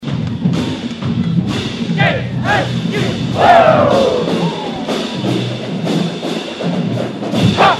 That’s the sound of the KSU marching band at the annual Mayor’s Spirit of the Holidays Lighted Parade.
Xmas-Parade-KSU-Band.mp3